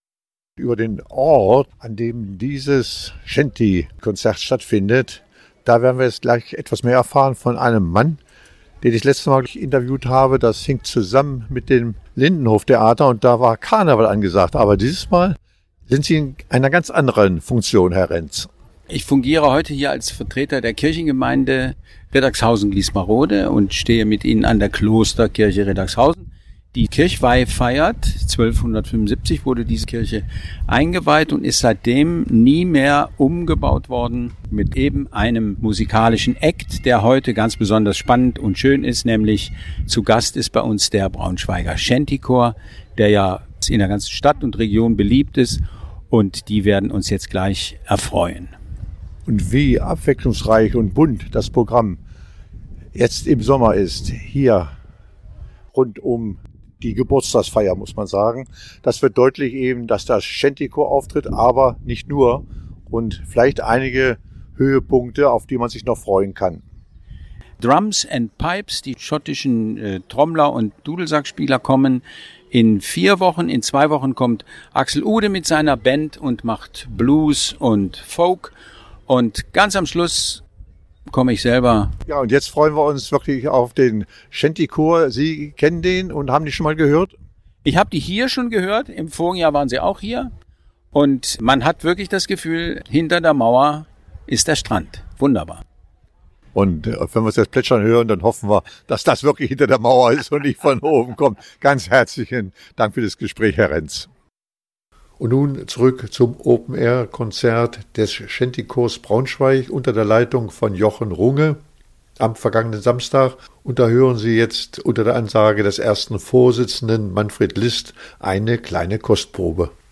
Shantymusik im Garten der Klosterkirche Riddagshausen
Zu Gast war am 28. Juni der Shantychor Braunschweig mit Seemannsliedern , bei denen man meinte, hinter der Klostermauer Wellen der rauen See plätschern zu hören.
Shantyklaenge-im-Klostergarten_kv_1.mp3